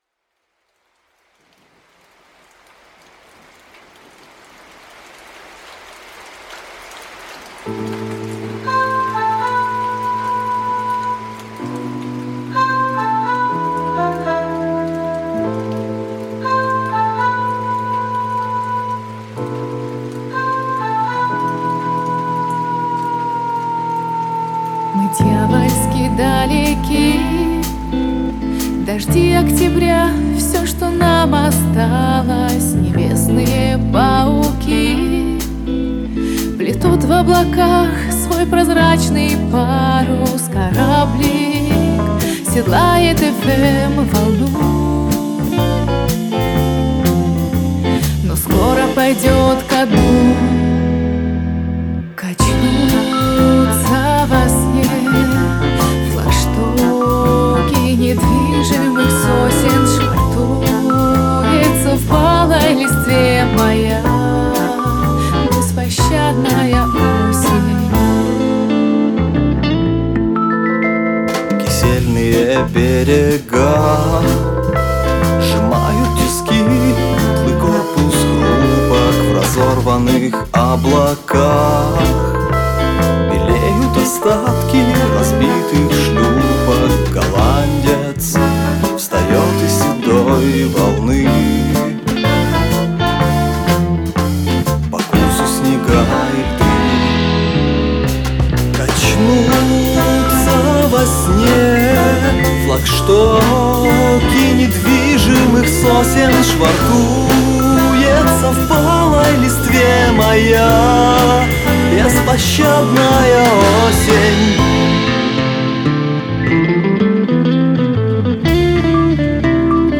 Может, конечно, все дело в обработке... но звучит действительно хорошо
smile ты хорошо поешь... вы оба 12 39 39 party